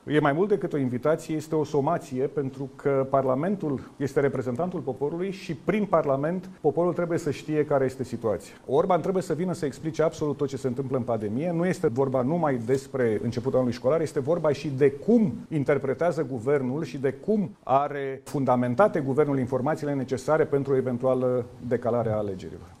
Lucian Romaşcanu, senator PSD şi purtător de cuvânt al partidului: